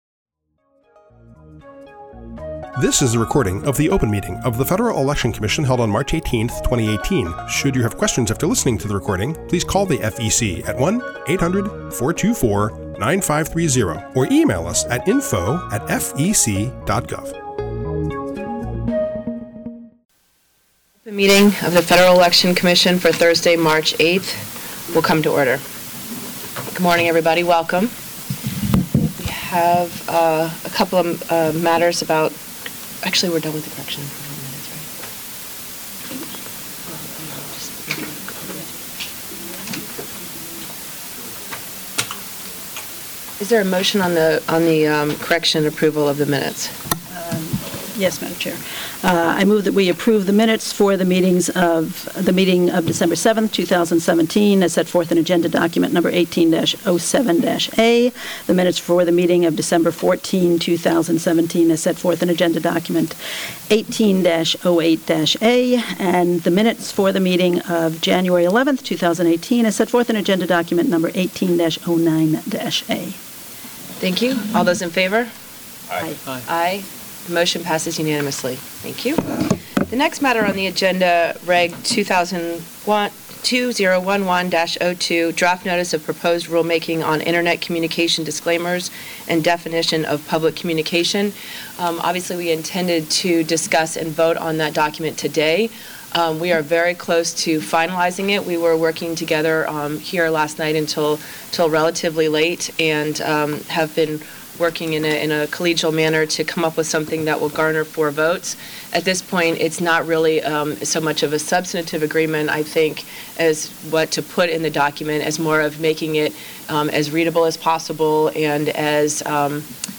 March 8, 2018 open meeting
The Commission considers new regulations, advisory opinions and other public matters at open meetings, which are typically held on Thursdays at 10:00 a.m. at FEC headquarters, 1050 First Street NE, Washington, DC.
Full meeting audio